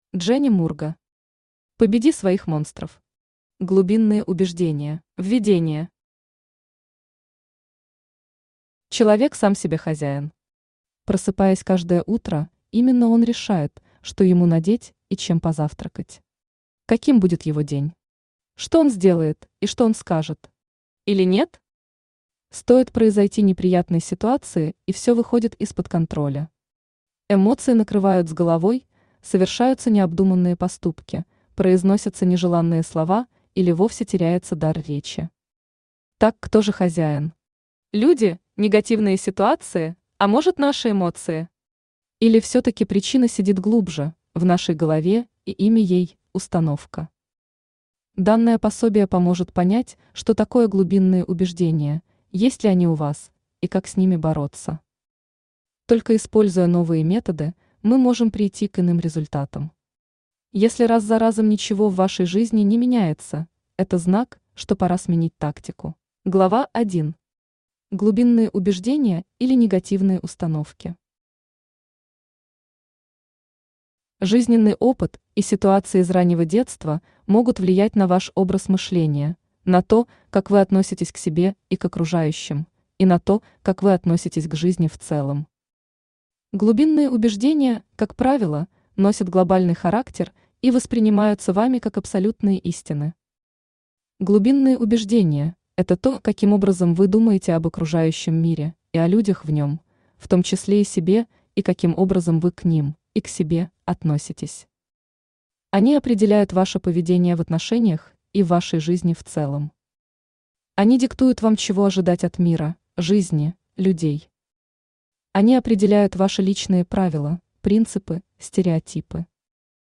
Глубинные убеждения Автор Джени Мурга Читает аудиокнигу Авточтец ЛитРес.